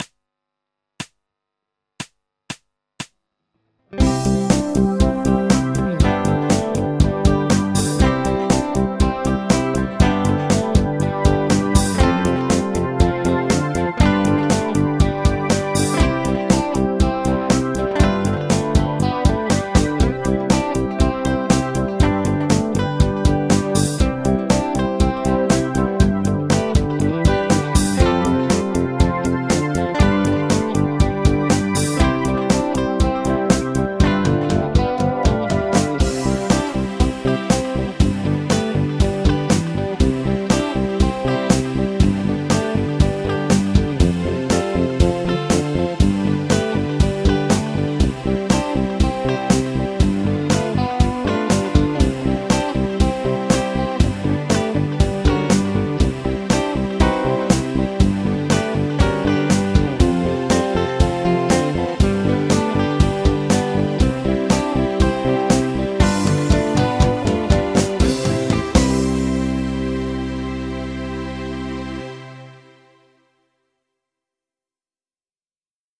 メジャー・ペンタトニック・スケール ギタースケールハンドブック -島村楽器